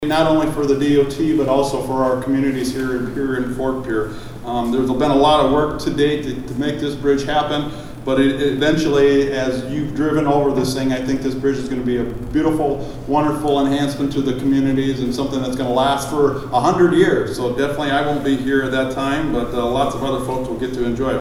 FORT PIERRE, (KCCR) — Local and state officials filled the Drifter’s dining room Tuesday to help dedicate the new Lieutenant Commander John C. Waldron Memorial Bridge. State Transportation Secretary Joel Jundt says the bridge completion is a milestone on many levels…